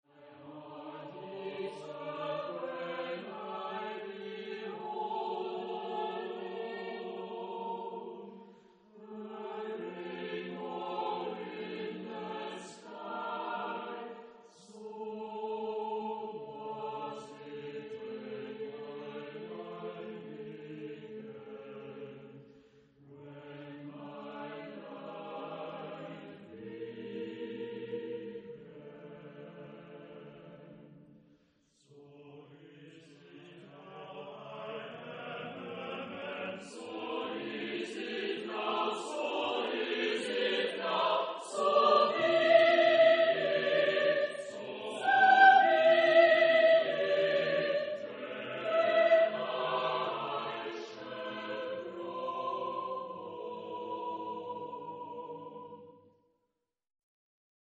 Genre-Style-Forme : contemporain ; Profane ; Chanson
Caractère de la pièce : mélodieux ; optimiste
Type de choeur : SATB  (4 voix mixtes )